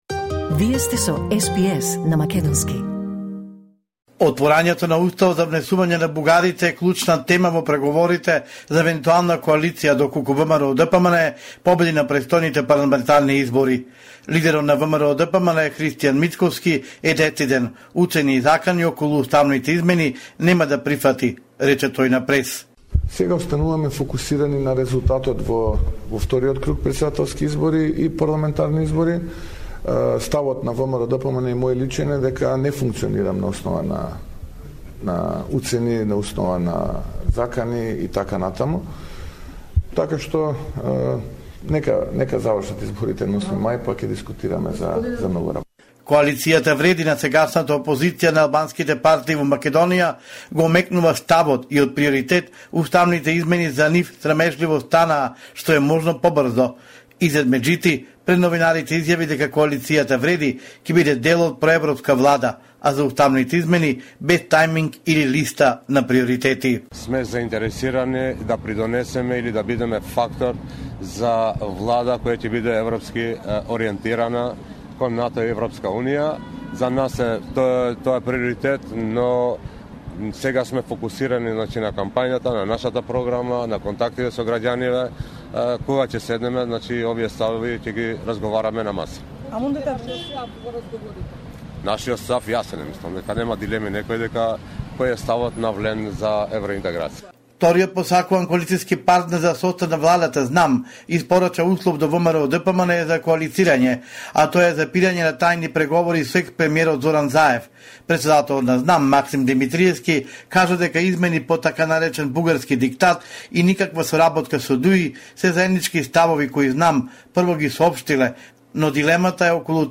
Homeland Report in Macedonian 1/05/2024